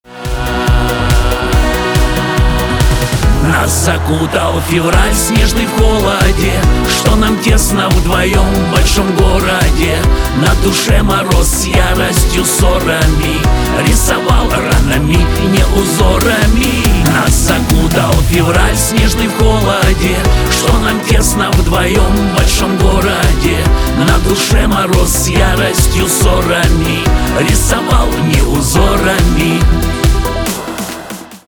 шансон
аккордеон